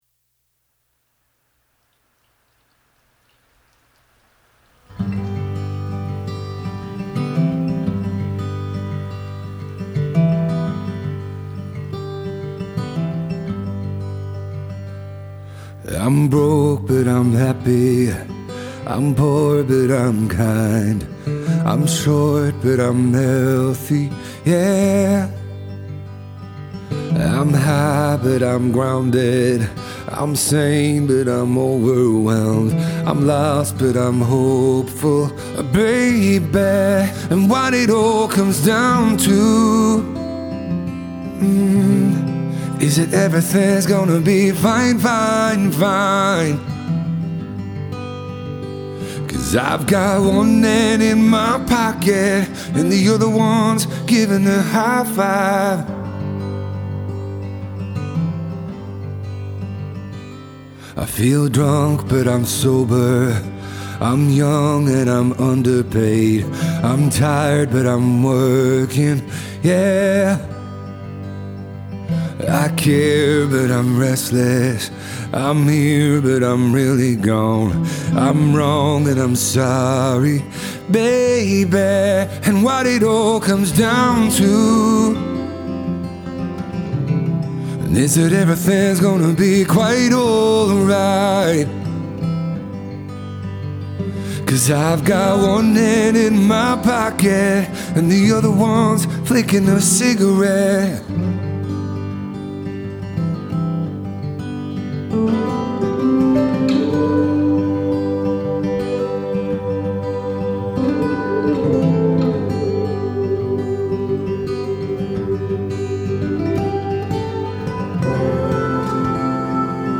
Solo | DJ | Looping | Guitar | Piano |
solo live acoustic performances.
soulful guitar playing and emotive vocal